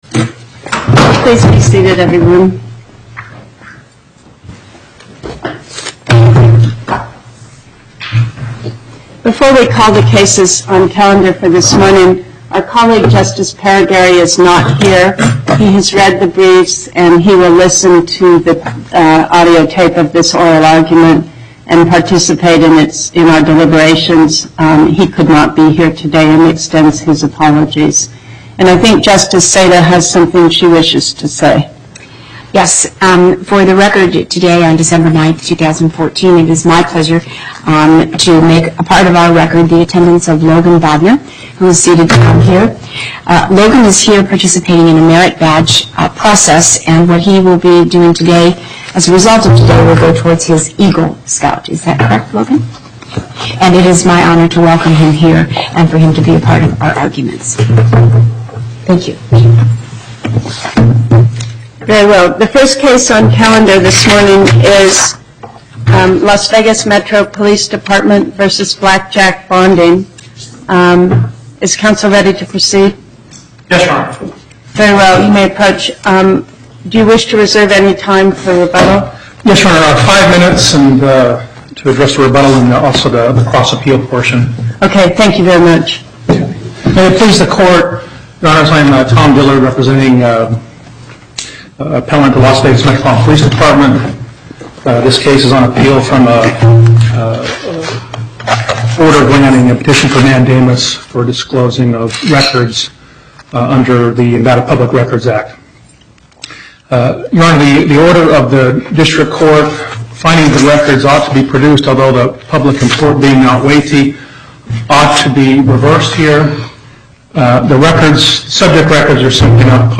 Location: Las Vegas Before the Northern Nevada Panel, Justice Pickering Presiding